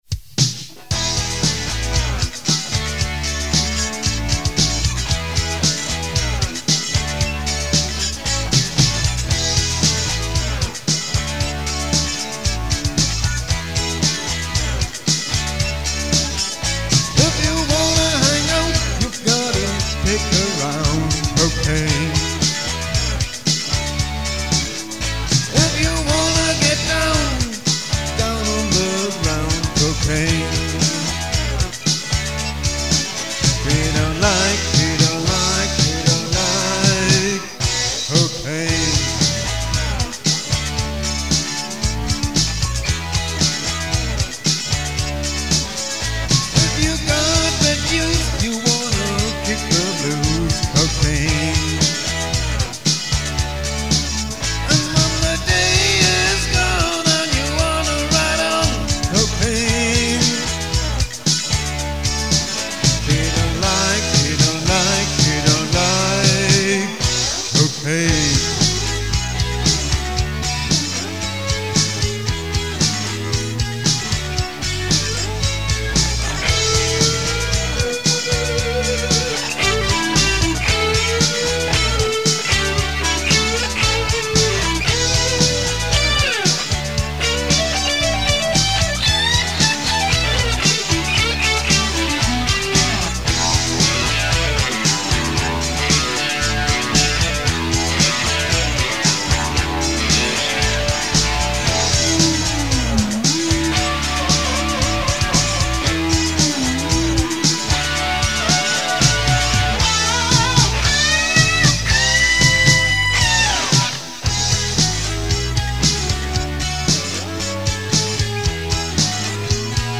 Das Schlagzeug wurde durch "Electronic Drums" realisiert.
Bass, Schlagzeug, 3 Gitarren, voc